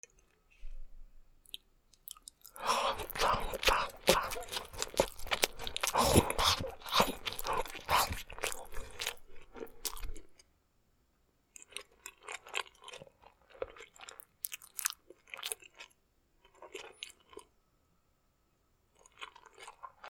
勢いよくパンを食べる